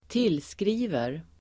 Uttal: [²t'il:skri:ver]